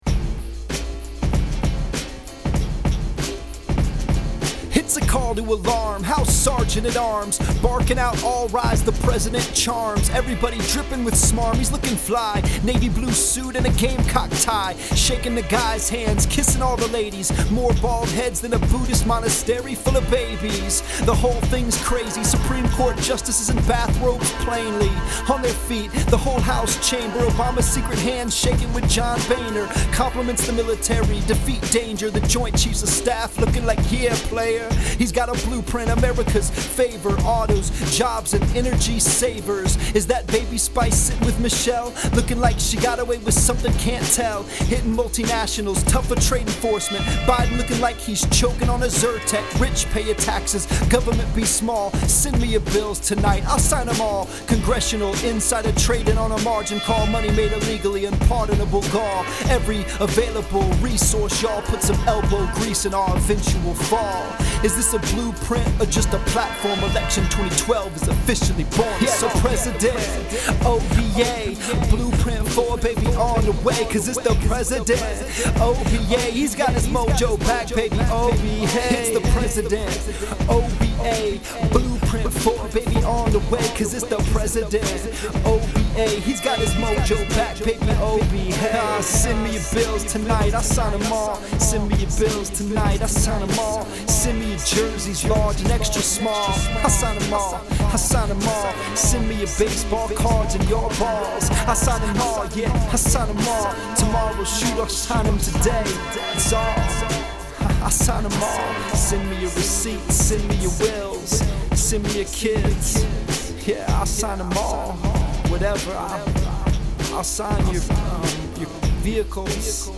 Anyway, in honor of the President’s “blueprint,” I paid homage to Jay-Z’s hook in the refrain for tonight’s blong.